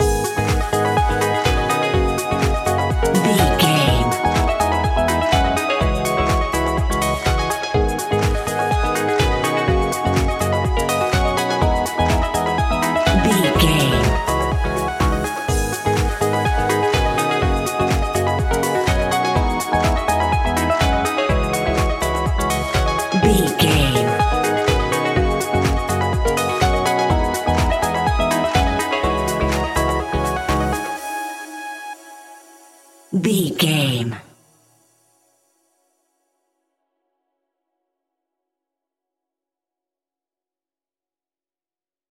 Aeolian/Minor
G#
groovy
uplifting
energetic
bouncy
synthesiser
drum machine
electro house
synth bass